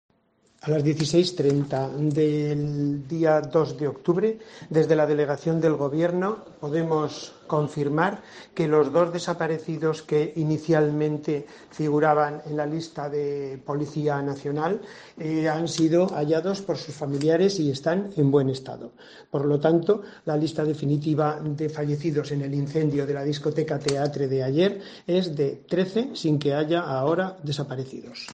Francisco Jiménez, Delegado del Gobierno en Murcia